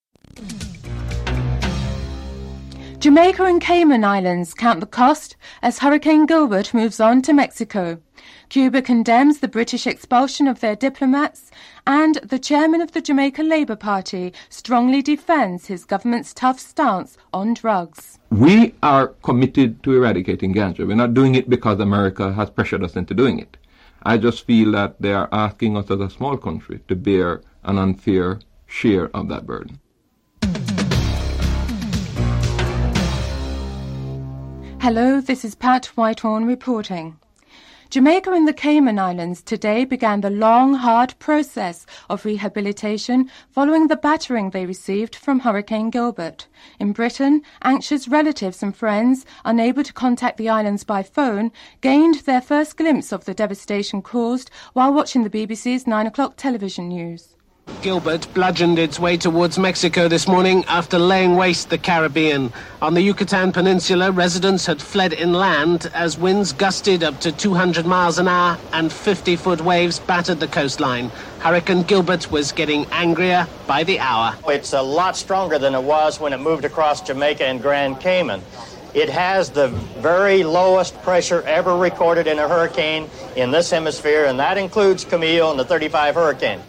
The report also features an interview with Bruce Golding, Chairman of Jamaica’s ruling Labour Party, on the government’s success in reducing Jamaica's ganja production from 15,000 tons in 1986 to approximately 300 tons in 1988. It concludes with a brief interview with Judy Simpson, Jamaican-born heptathlete, at a training camp near Tokyo in her preparation for the Seoul Olympics.